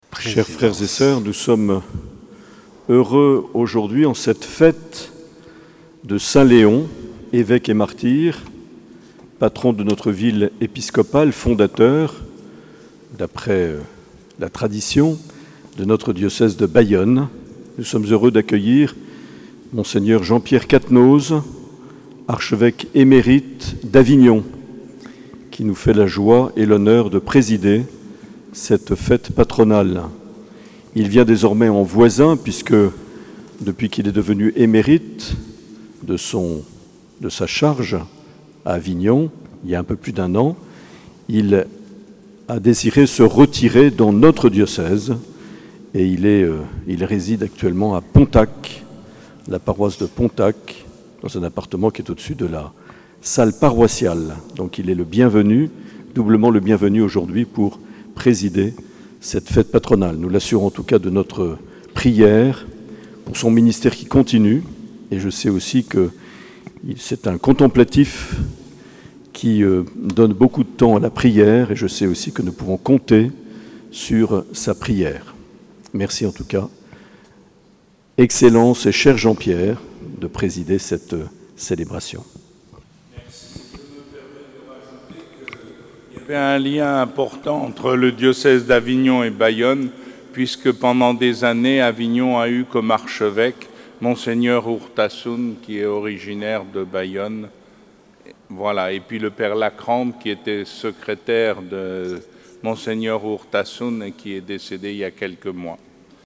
Mot d’accueil de Mgr Marc Aillet.